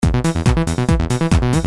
Qui potrete trovare files in formato .rbs e .wav da usare in modo loop, per poterli edittare, trasformare, oppure elaborare in sequenza con programmi appropriati, che sicuramente, gli appassionati a questo tipo di lavoro conoscono molto bene.
Sequence mp3